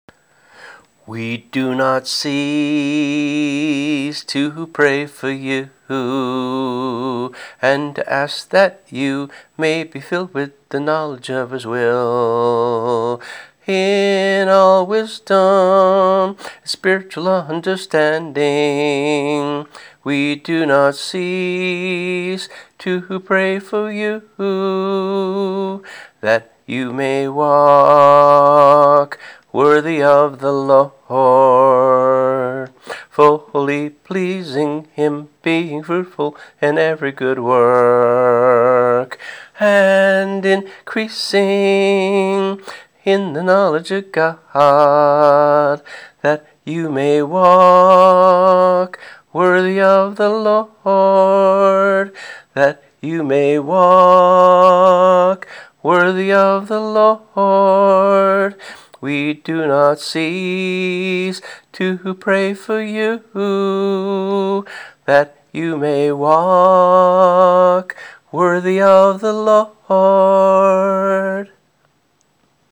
[MP3 - vocal only]